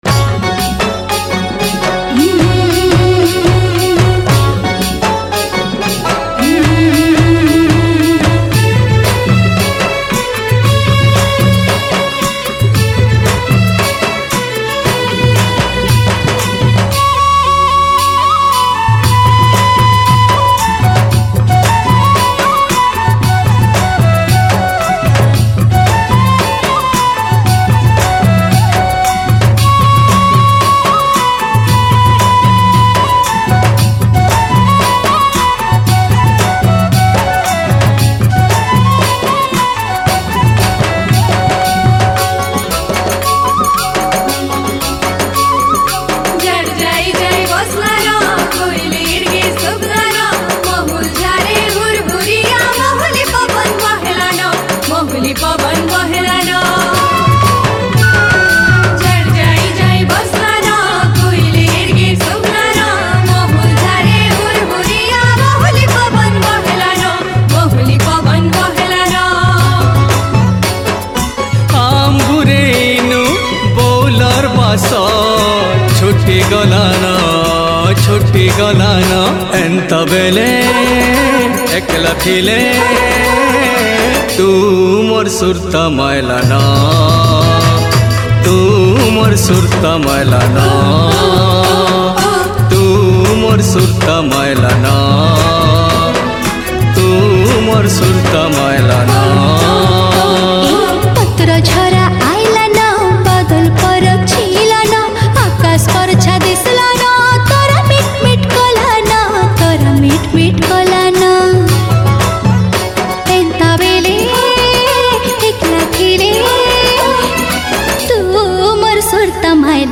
Sambalpuri Songs